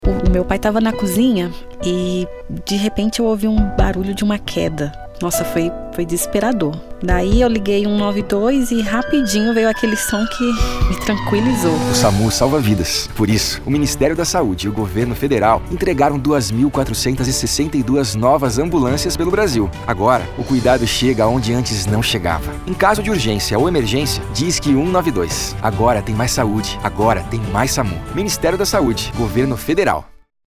Áudio - Spot 30s - Agora Tem Mais SAMU 192 .mp3 — Ministério da Saúde